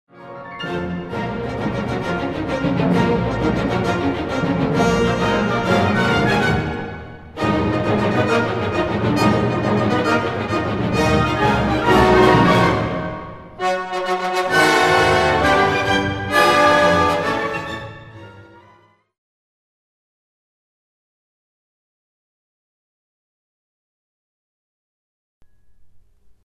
Liszt-Orchesterepilog.mp3